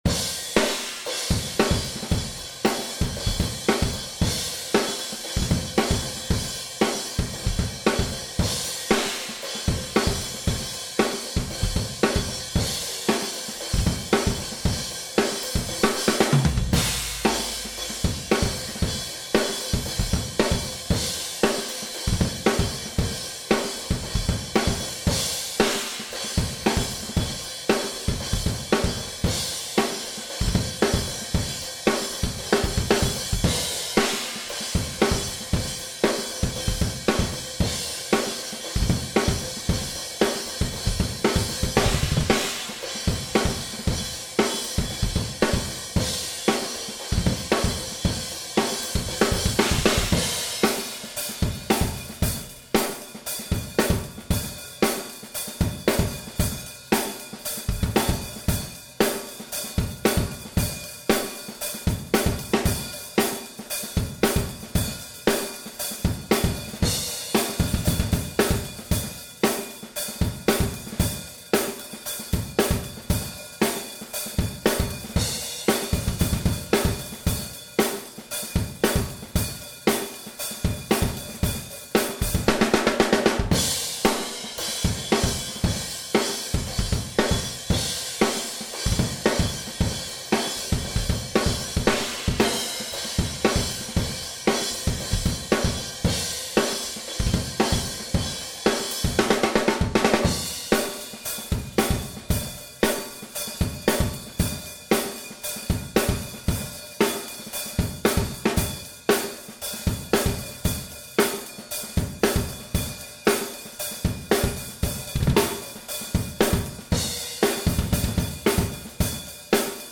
la prise batterie audio d'un de nos morceaux (la generation des donneurs de leçons). je n'ai laissé que les 4 pistes d'ambiances, comme ça pas de triche sur le son.
2 micros devant et 2 sur les cotès , très, mais alors trèèès loin ! (C01 samson, pour les micros)